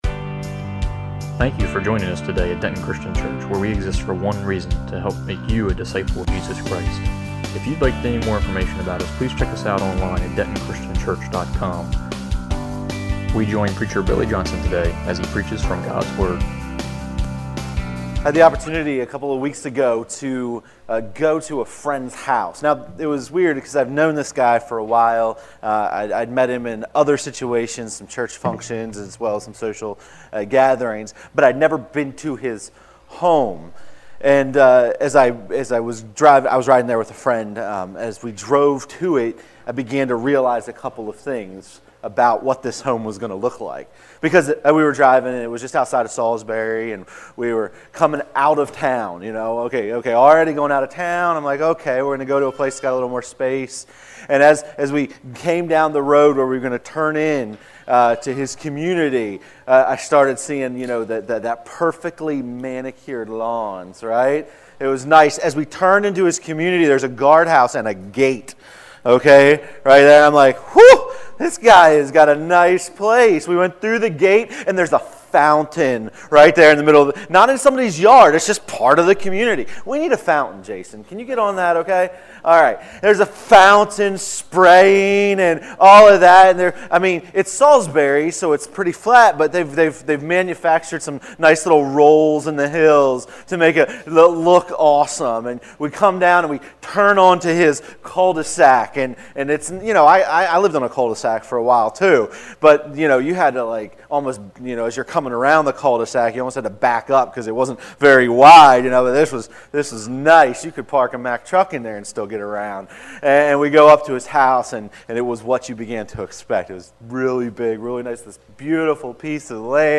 All Sermons , Jesus by John Book John Watch Listen Save John gives an amazing introduction to the person of Jesus Christ.